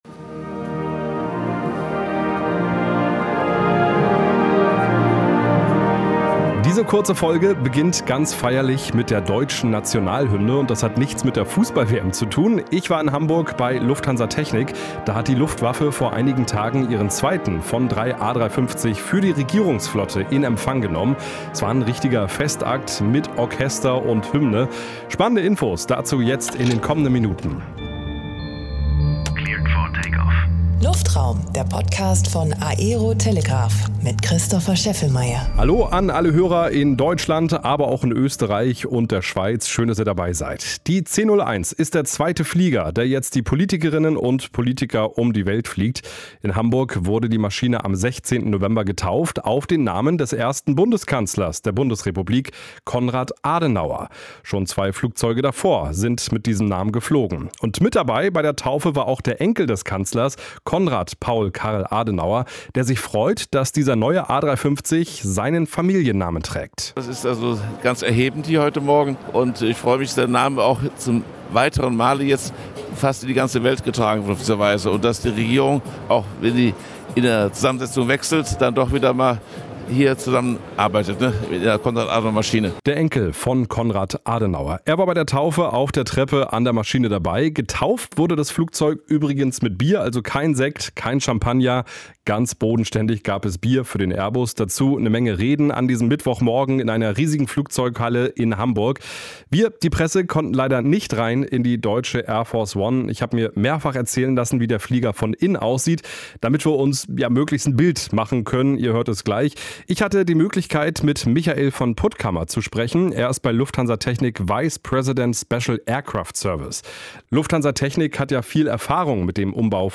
Ich war für euch bei der Taufe in Hamburg bei Lufthansa Technik dabei.